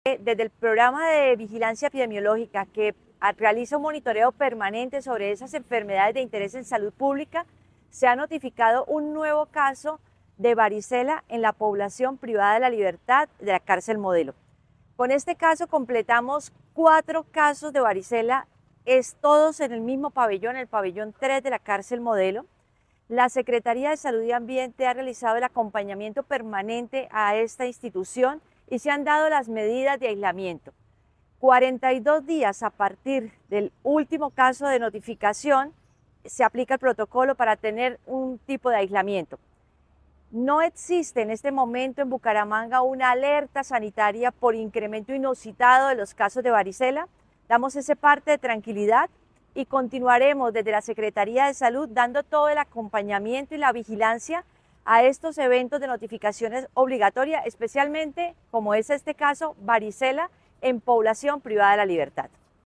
Claudia Amaya, secretaria de salud y ambiente de Bucaramanga